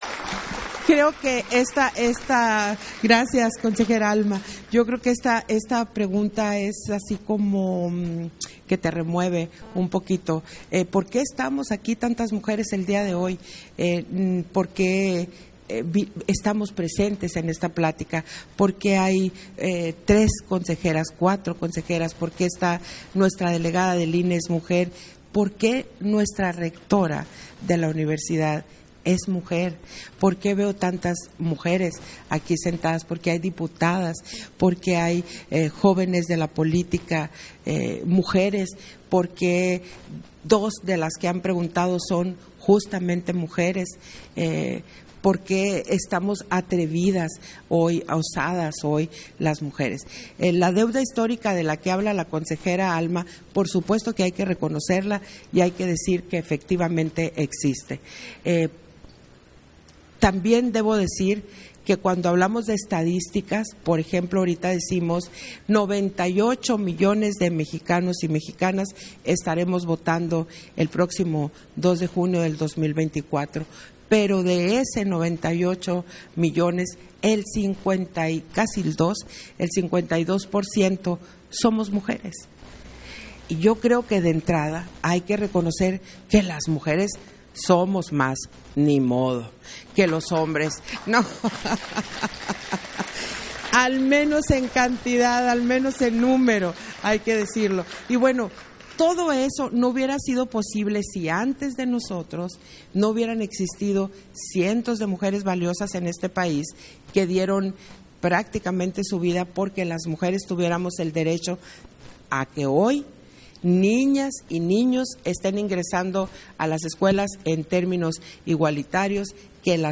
Intervenciones de Guadalupe Taddei, en el Foro Juventud y Democracia